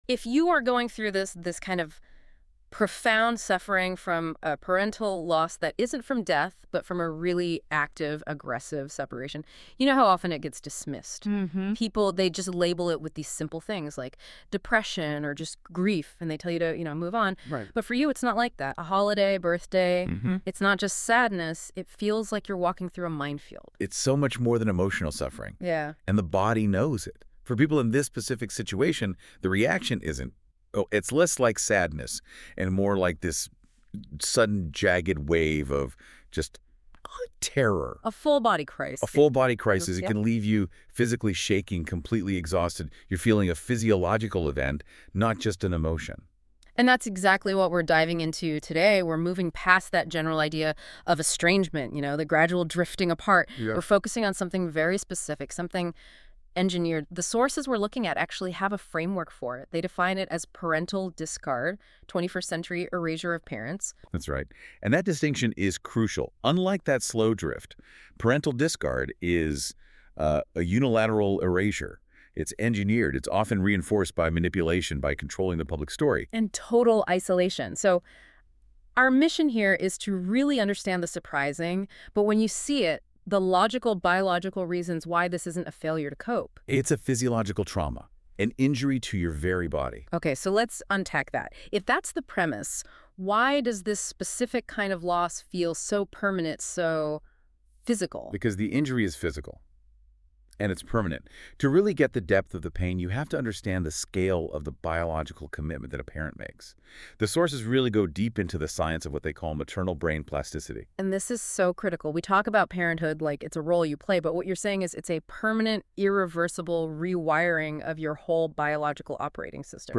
Listen: Beyond Depression – The Biological Truth Listen to this article narrated with additional insights for parents on the go